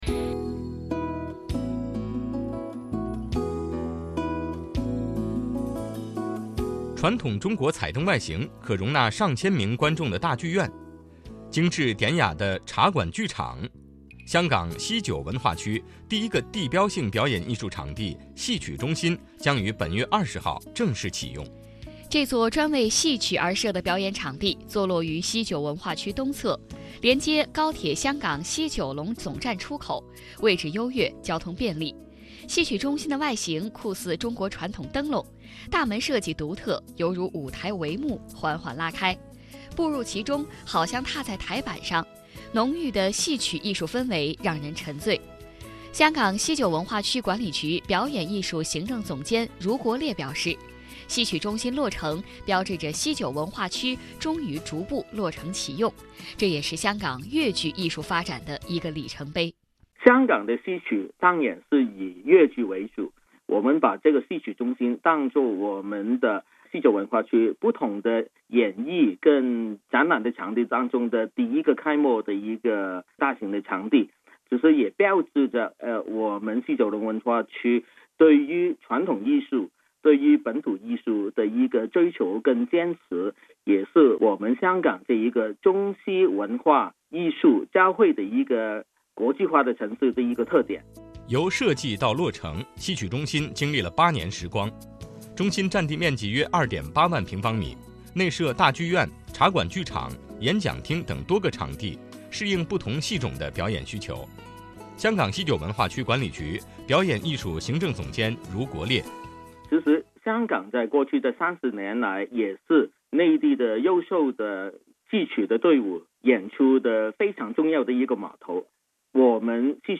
随着两地交流日益扩大，驻京办加强了在华北地区的宣传和推广工作，并自2006年起与中央人民广播电台「华夏之声」（2019年9月起更名为中央广播电视总台大湾区之声）携手打造普通话广播节目「每周听香港」，在华北九个省、市、自治区级电台播出，以趣味与信息并重的形式，把香港的最新发展带给当地听众。